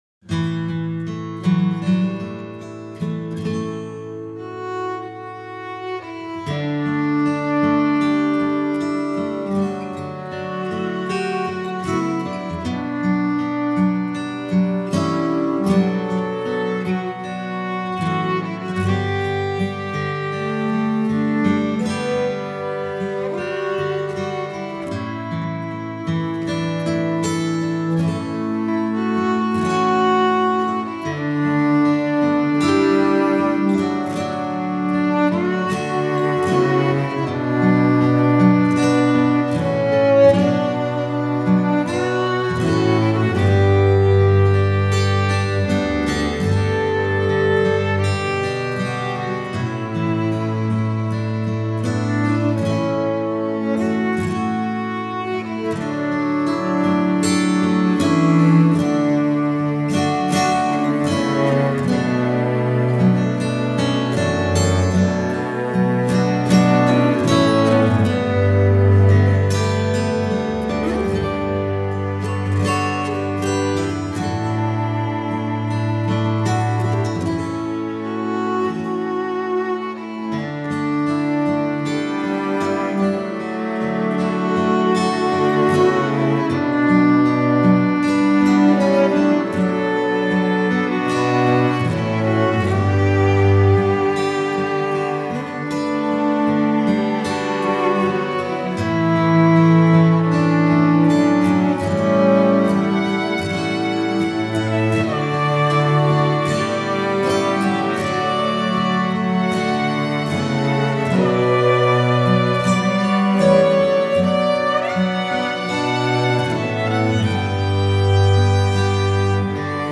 Instrumental   II Thessalonians 3:16; Isaiah 57:19 Chorus The Peace of our Lord, Jesus Christ String Quartet
The-Lords-Peace-Instrumental.mp3